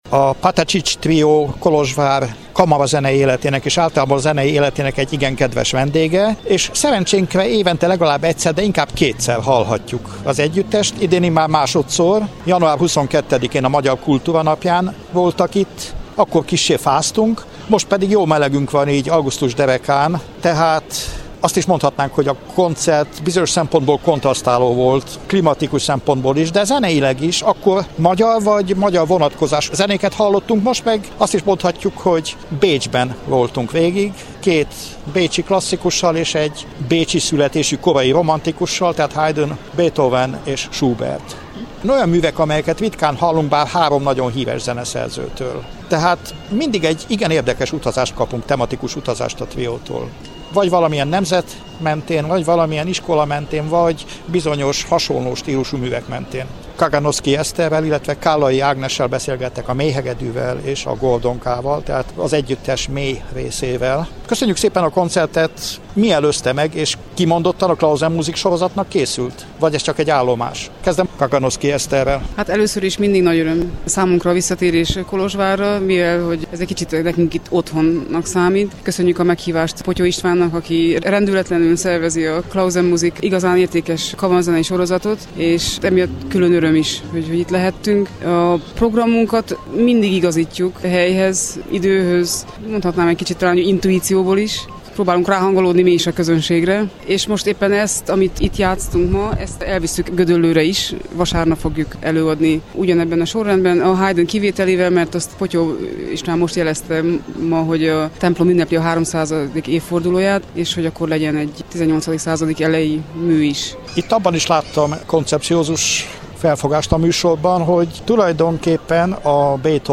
Pénteki koncertjük után beszélgettünk a trió két tagjával.